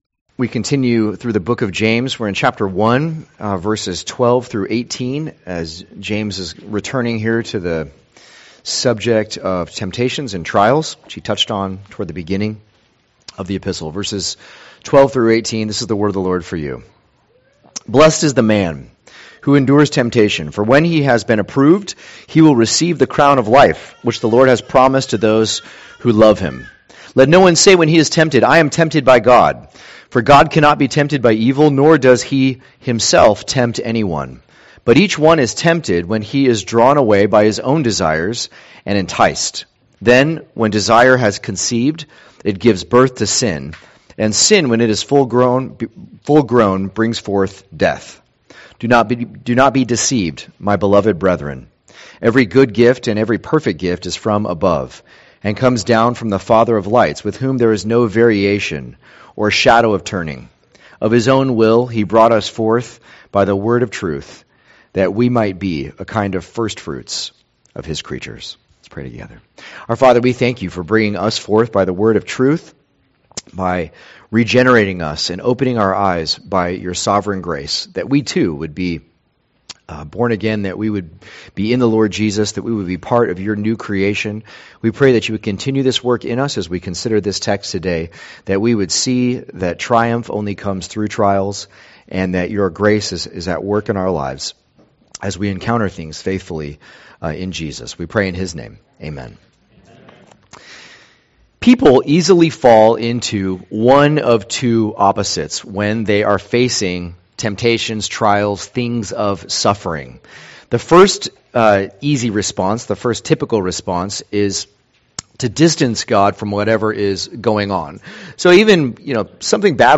2023 Trials & Triumph Preacher